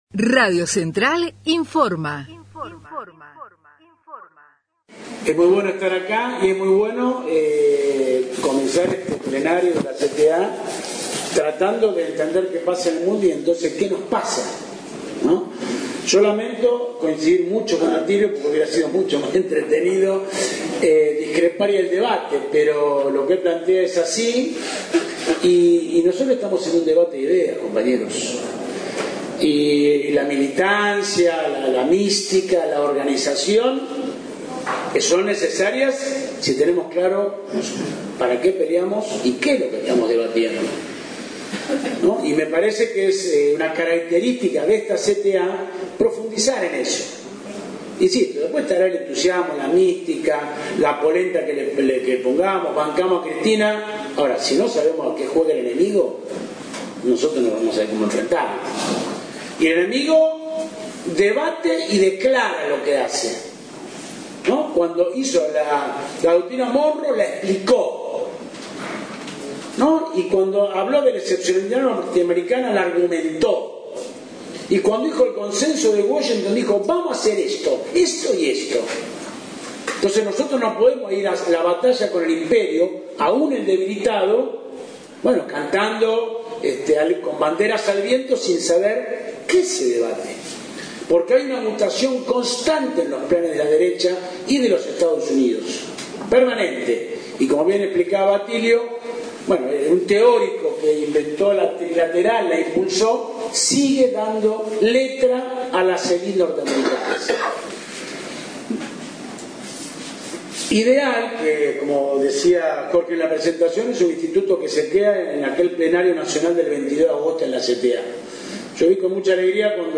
PLENARIO NACIONAL CTA (en Santa Fe) – Central de Trabajadores y Trabajadoras de la Argentina